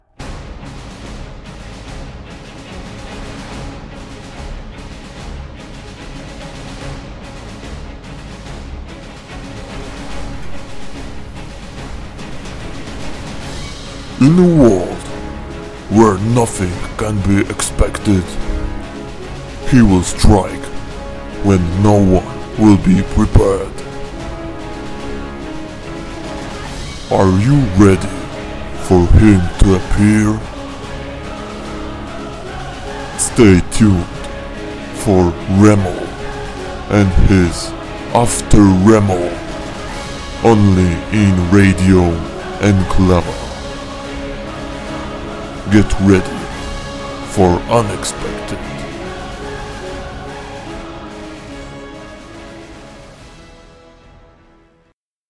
Trailer
A na końcu stopniowo wygasała.
NIestety obecny mikrofon powoduje, że albo muszę się mocno wydzierać, co jest nie na rękę innym domownikom, albo muszę przystawiać go bliżej ust, przez co pojawiają się trzaski i nieco tłumi możliwość zabawy głosem.
- Muzyka niepotrzebnie przedłużona na początku i na końcu, powinna wypełniać i urozmaicać ciszę, ale nie pełnić głównej roli.